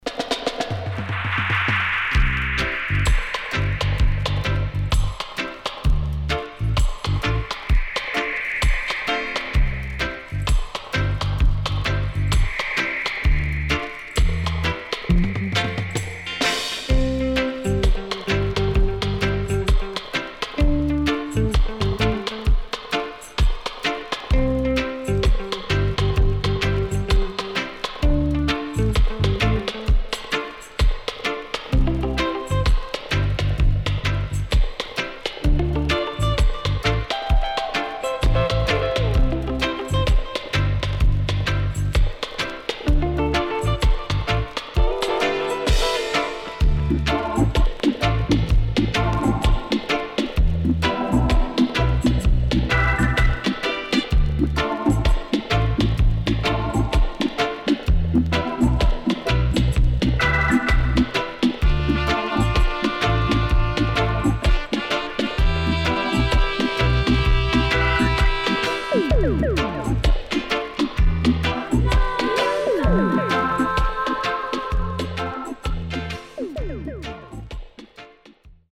UK Lovers Classic
SIDE A:うすいこまかい傷ありますがノイズあまり目立ちません。